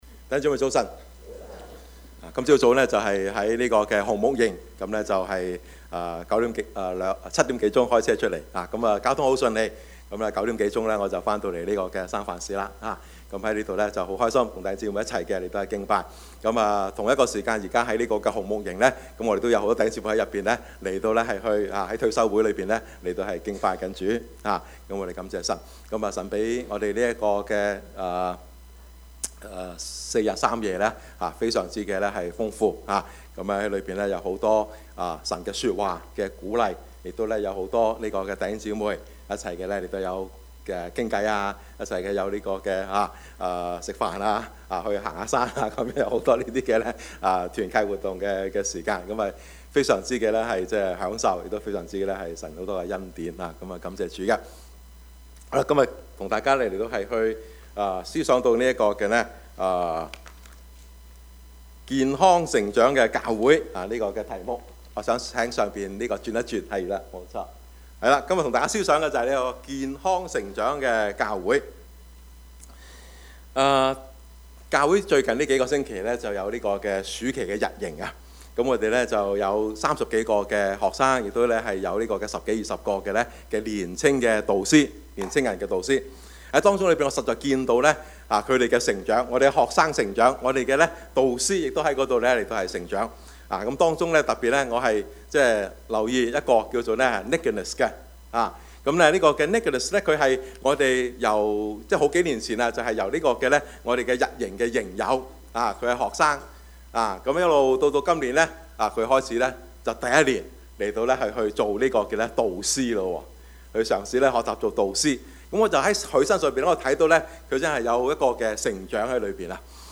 Service Type: 主日崇拜
Topics: 主日證道 « 新人舊人 神的兒女 »